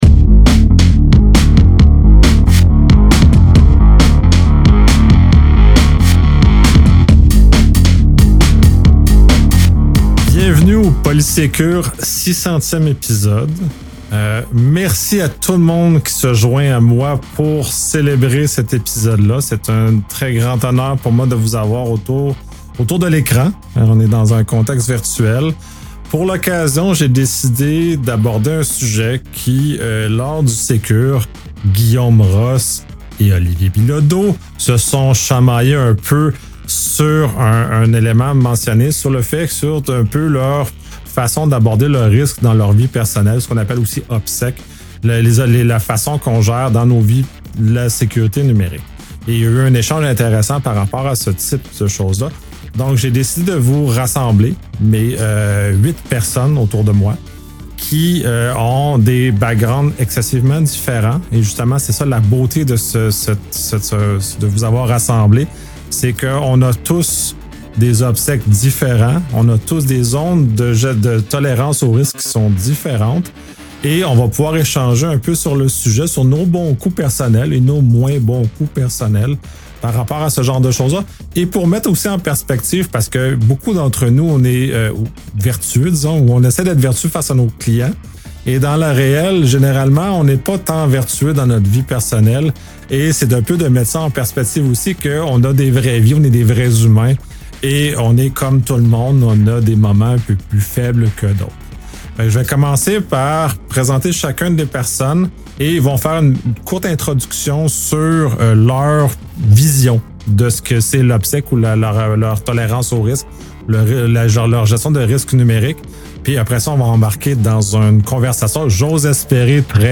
Le 600e épisode du podcast Policesécure réunit une assemblée d’experts en cybersécurité pour aborder un sujet particulièrement pertinent : l’obsec (operational security) personnel et la façon dont les professionnels de la sécurité gèrent leurs propres risques numériques.